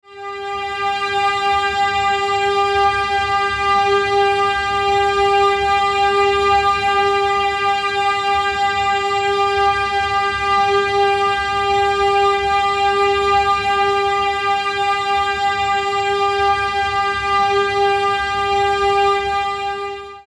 Music tones for String Animation Below
25_High_G.mp3